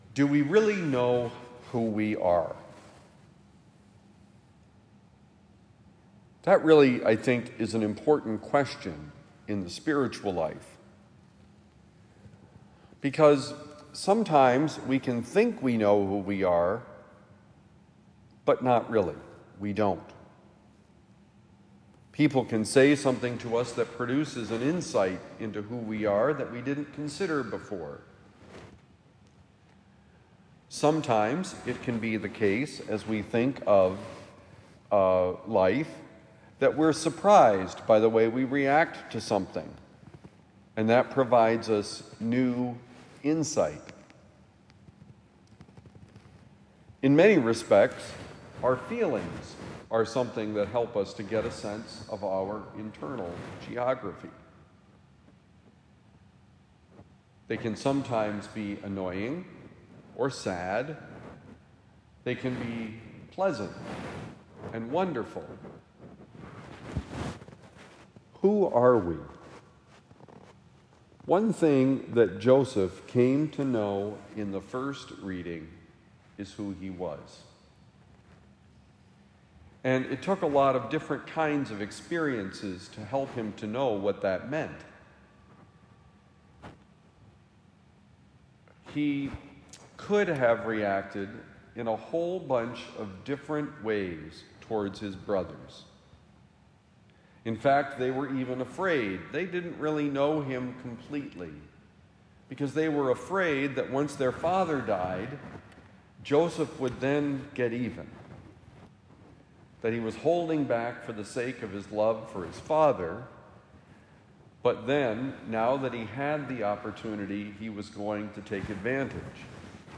Joseph knew who he was: Homily for Saturday, July 12, 2025